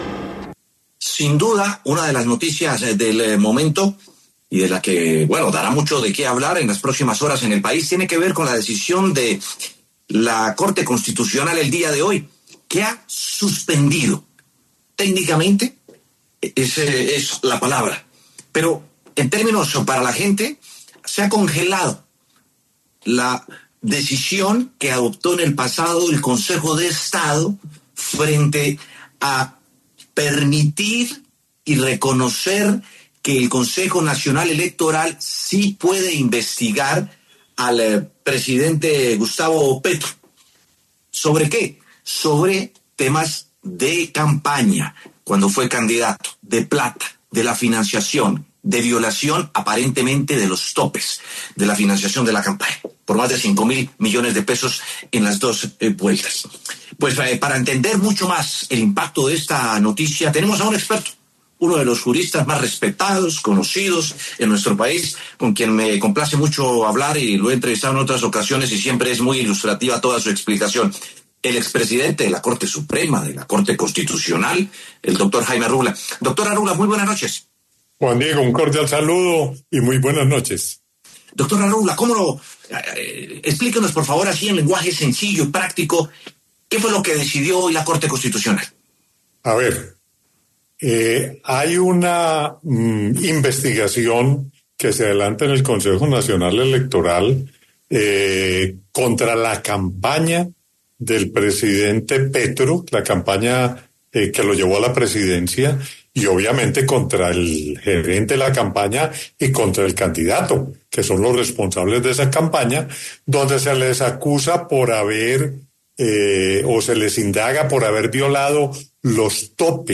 Para analizar este tema, pasó por los micrófonos de W Sin Carreta el exmagistrado de la Corte Suprema de Justicia Jaime Arrubla, quien expresó su punto de vista.